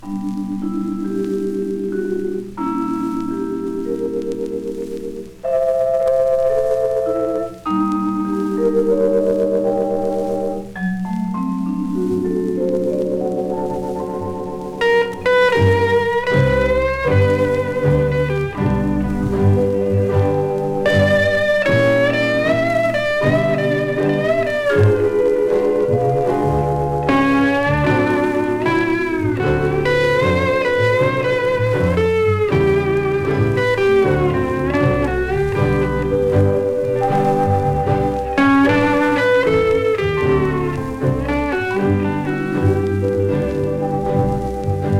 当時のハワイから送られてきた、一瞬でトリップする猛烈な観光地音楽集。
World, Hawaii　USA　12inchレコード　33rpm　Stereo